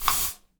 spray_bottle_12.wav